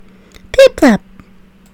Audio / SE / Cries / PIPLUP.mp3